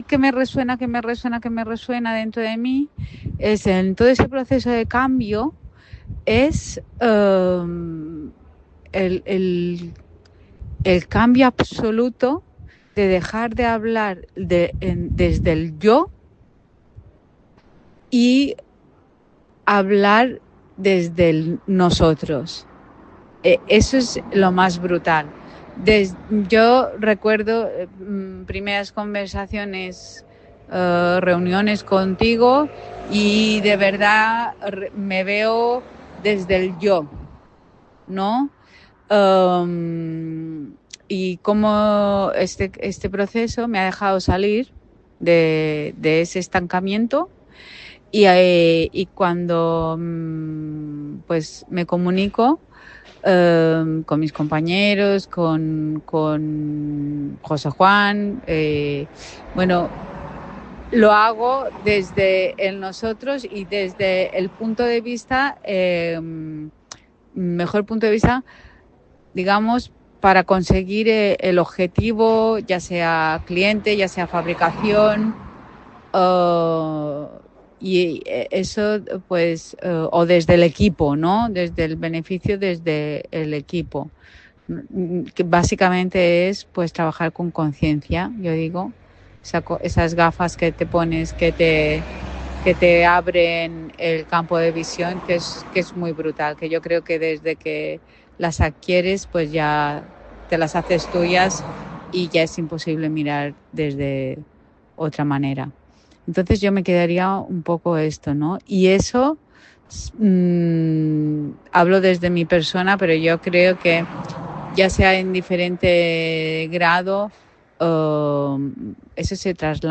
Capítulo 7.  Testimonio de la CO-CEO